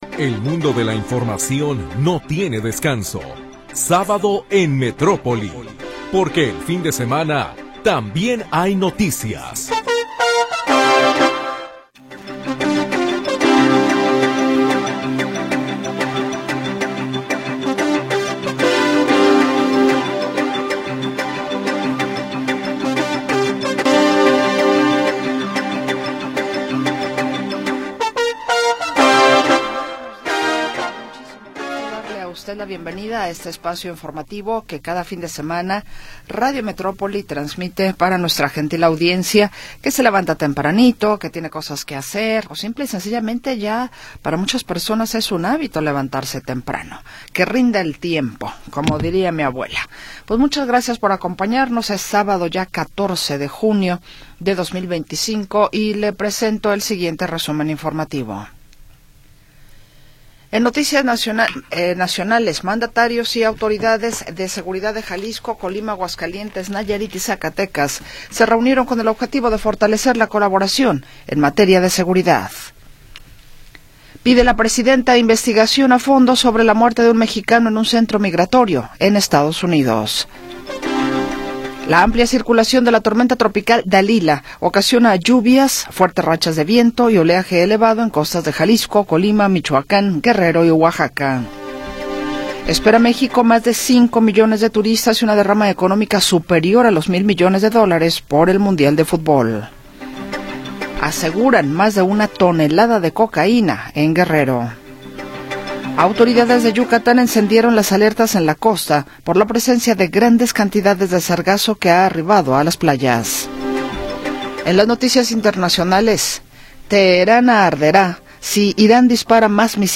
Primera hora del programa transmitido el 14 de Junio de 2025.